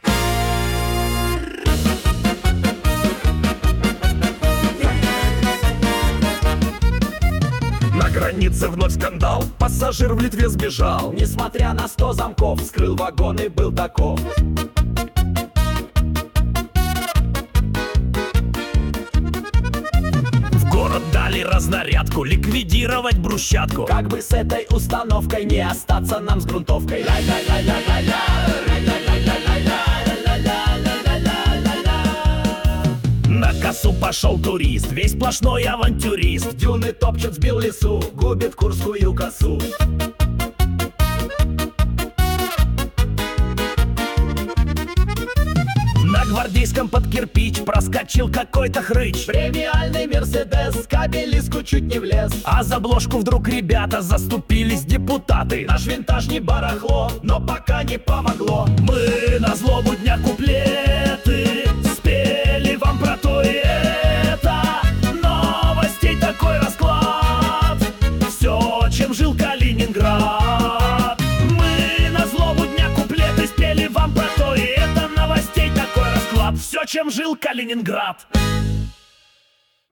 Куплеты на злобу дня о главных и важных событиях